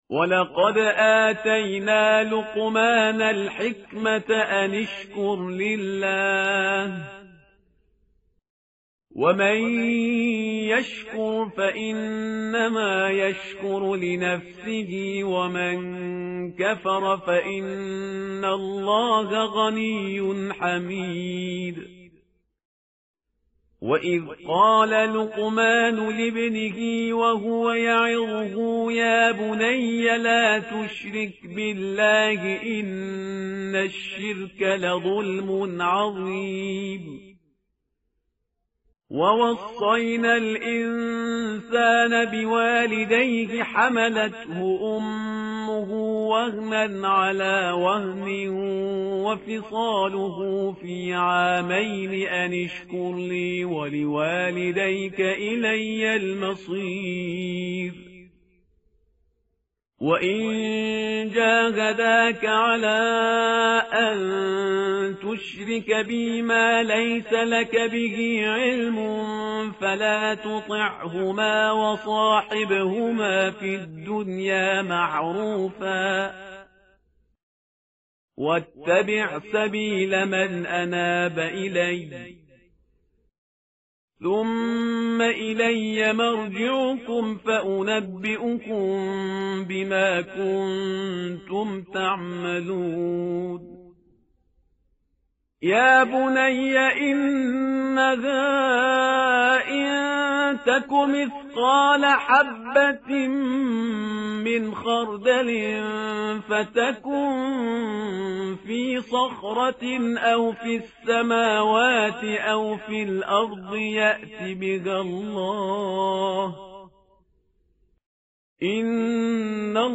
tartil_parhizgar_page_412.mp3